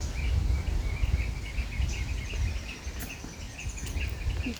Mosqueta Ceja Amarilla (Capsiempis flaveola)
Nombre en inglés: Yellow Tyrannulet
Localidad o área protegida: Parque Provincial Teyú Cuaré
Condición: Silvestre
Certeza: Fotografiada, Vocalización Grabada